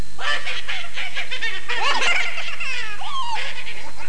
home *** CD-ROM | disk | FTP | other *** search / Horror Sensation / HORROR.iso / sounds / iff / chuckle2.snd ( .mp3 ) < prev next > Amiga 8-bit Sampled Voice | 1992-09-02 | 82KB | 1 channel | 19,886 sample rate | 4 seconds
chuckle2.mp3